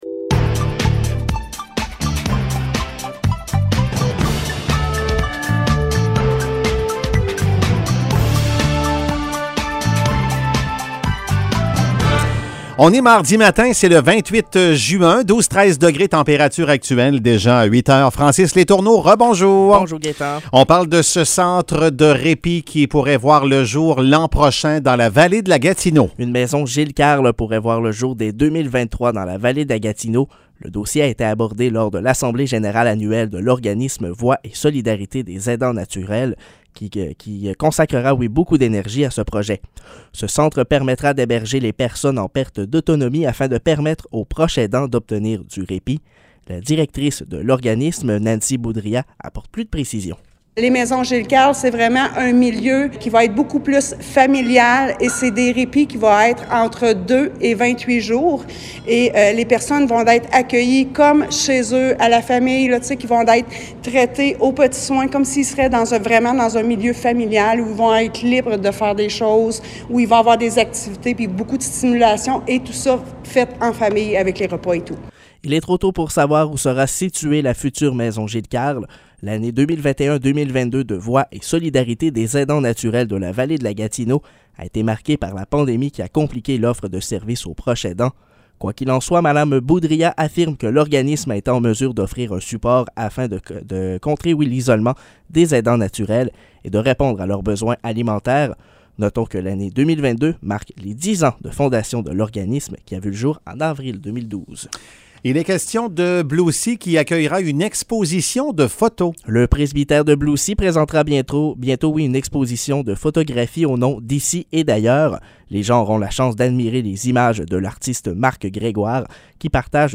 Nouvelles locales - 28 juin 2022 - 8 h